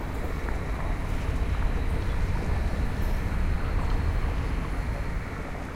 Sinkhole.ogg